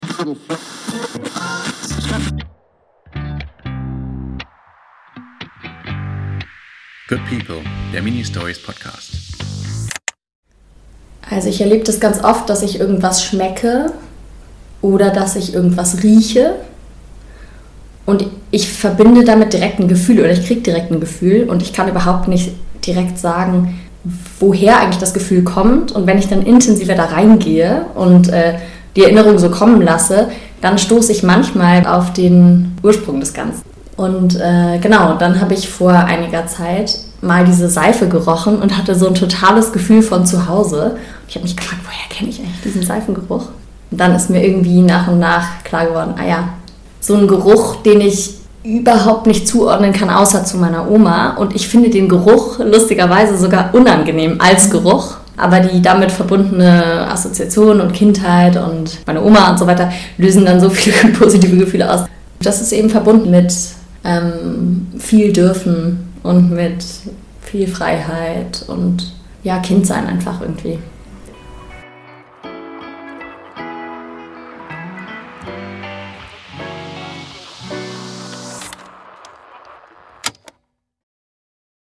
Aus einem Gespräch in einer WG-Küche entstanden sieben porträtierende Folgen über die rund 30-Jährige. In der ersten Folge spricht sie über eine Seife, die in ihr positive Gefühle wachrufen, obwohl sie den Duft nicht mag.